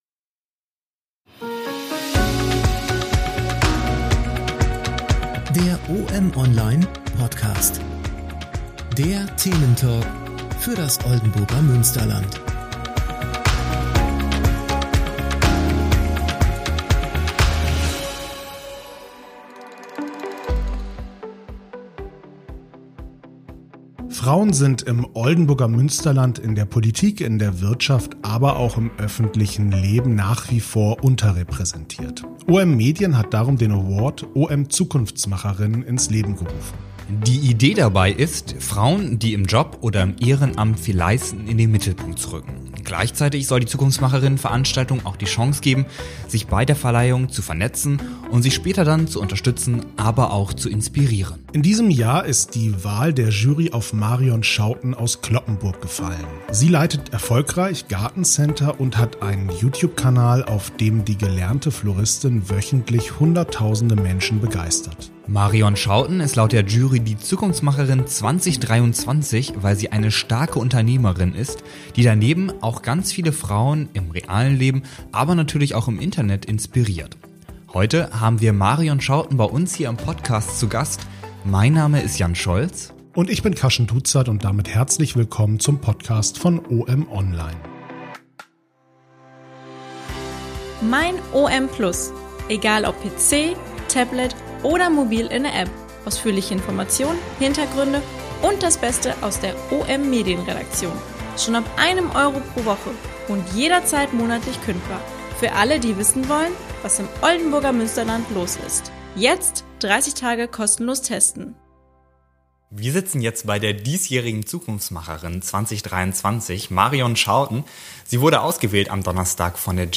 In dieser Podcast-Folge sprechen die Moderatoren